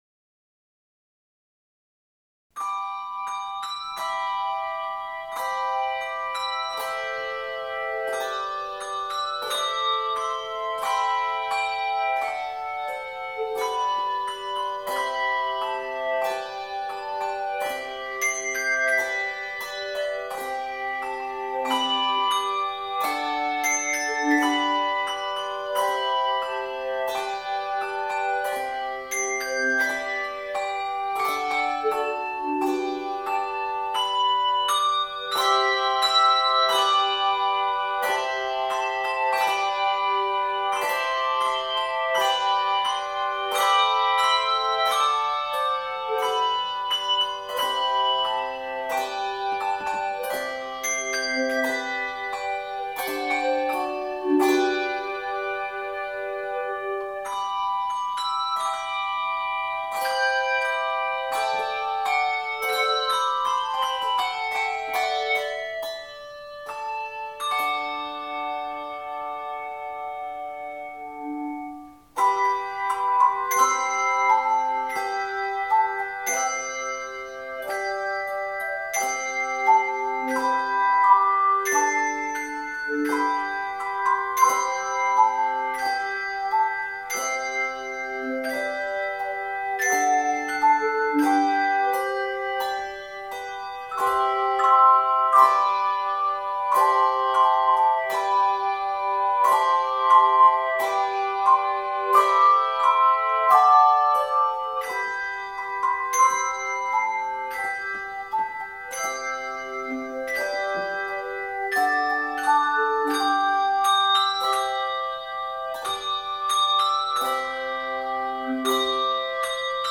The piece also incorporates choirchimes.